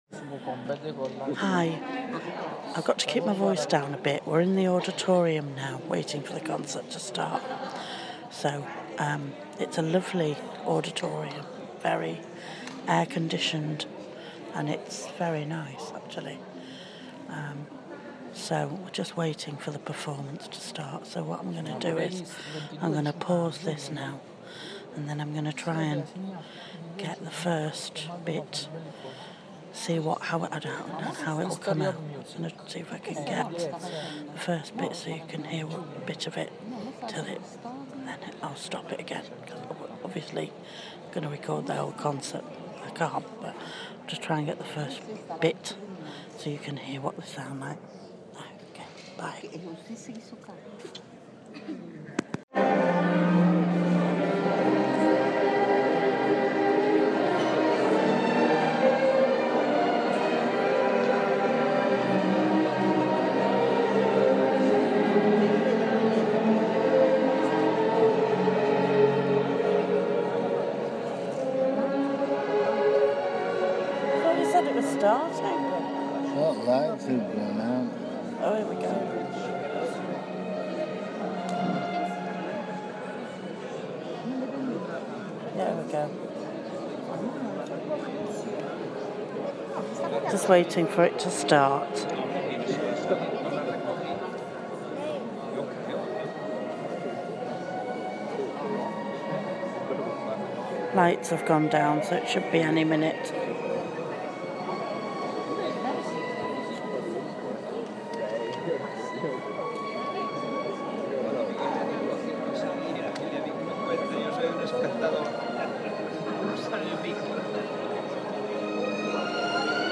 From last night's concert